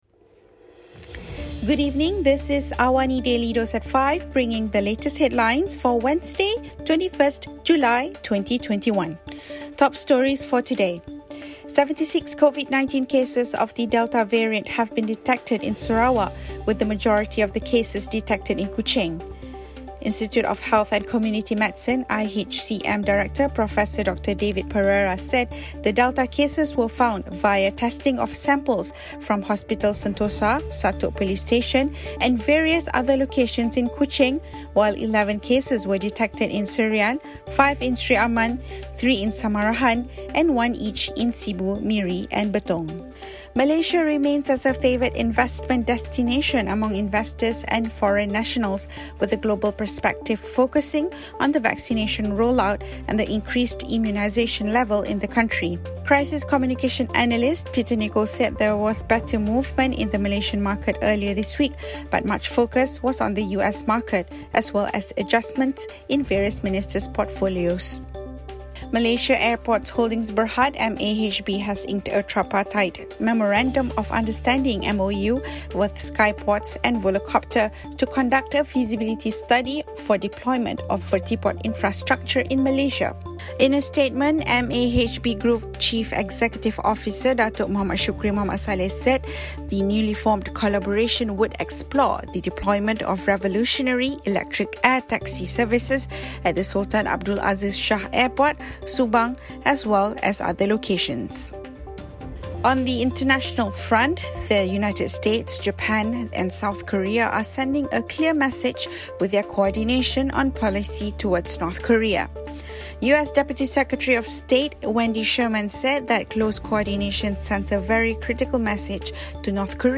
Also, The United States, Japan and South Korea are sending a clear message with their coordination on policy towards North Korea. Listen to the top stories of the day, reporting from Astro AWANI newsroom — all in 3 minutes.